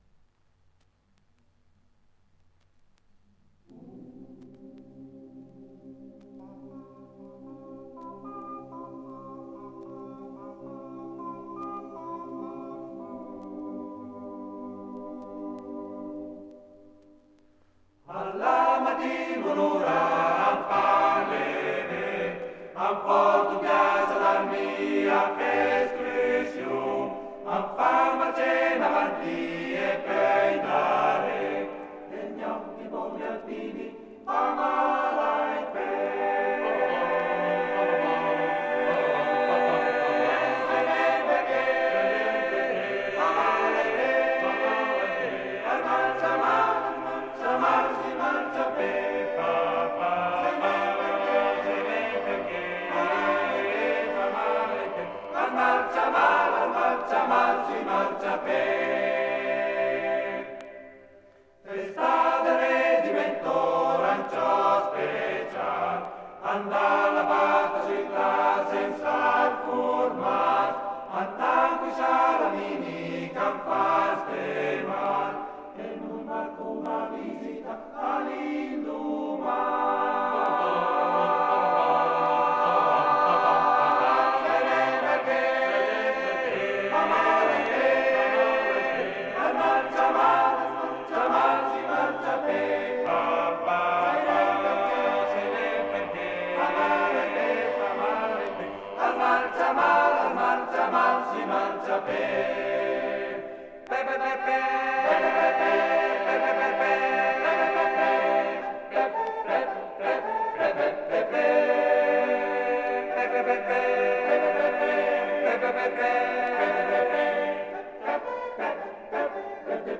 Esecutore: Coro Grigna dell'Associazione Nazionale Alpini sez. di Lecco